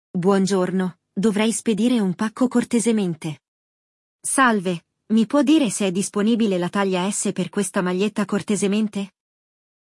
No episódio de hoje, vamos acompanhar uma conversa entre uma senhora e um vendedor de frios numa loja: eles falam sobre os produtos em oferta, medidas e preços.
In questo episodio di Walk ‘n’ Talk Level Up, hai ascoltato il dialogo tra una signora e un salumiere: parlano di prodotti da banco frigo e nello specifico, di un prodotto in offerta.